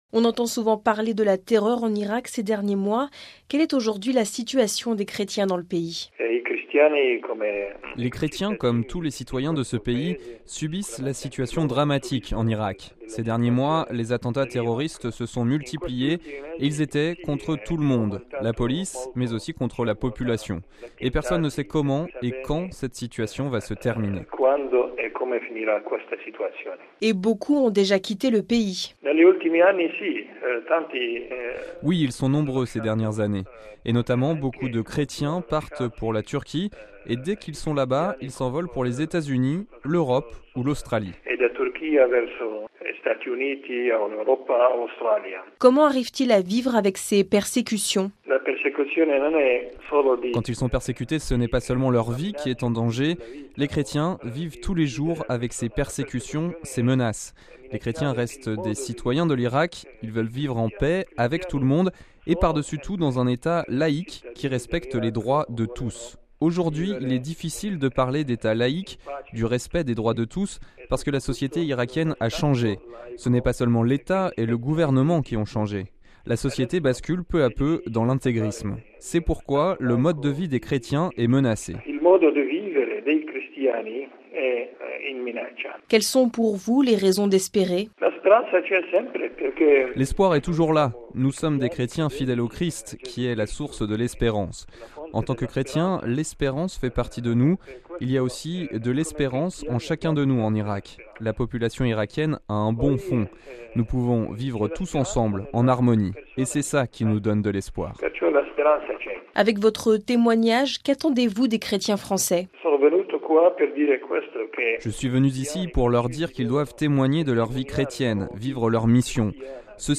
Parmi eux il y a Mgr Amel Shamon Nona, archevêque de Mossoul en Irak.